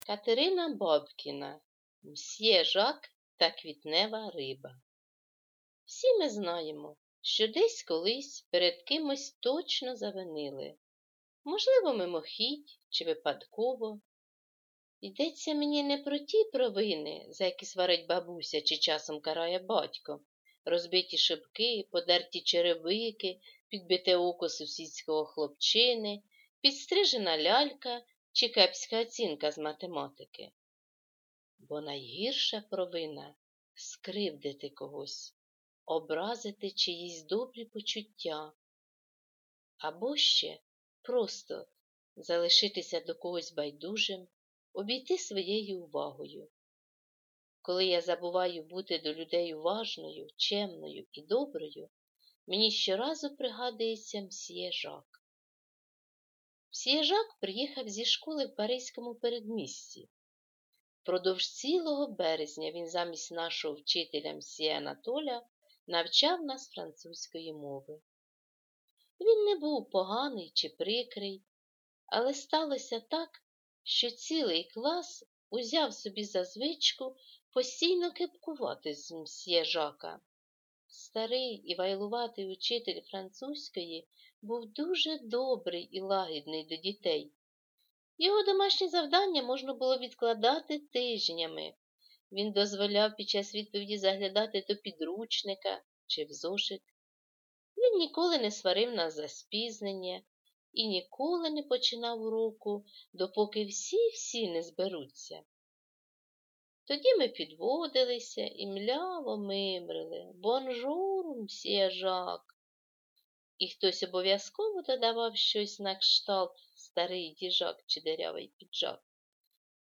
1) Прослуховування початку твору в аудіозапису (читає учитель);
Читання вчитель